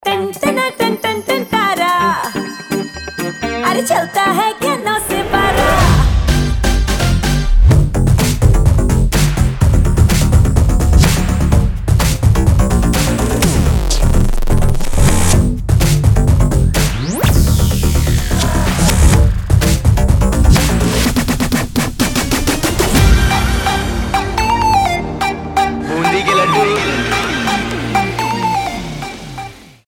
• Качество: 320, Stereo
мужской голос
женский вокал
dance
Electronica
bollywood